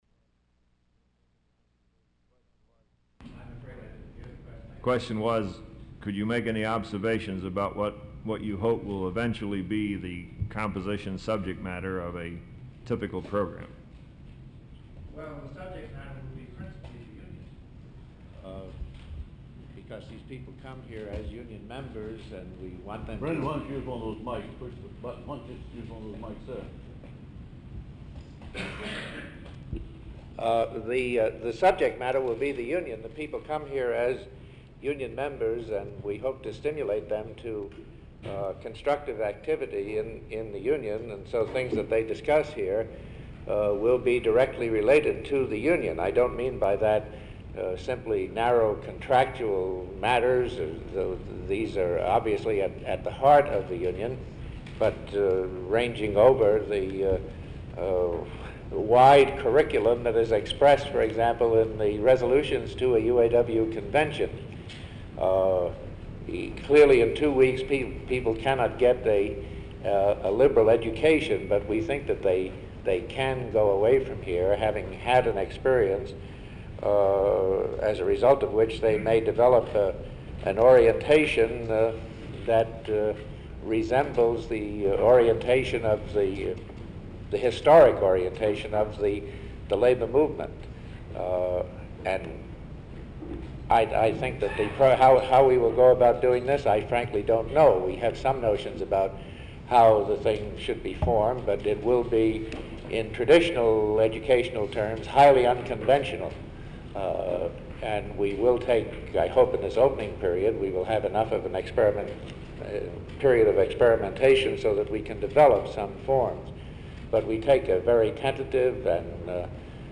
UAW President Leonard Woodcock - Press Conference before United Nations Conference took place - Black Lake, Family Education Center, Reel 2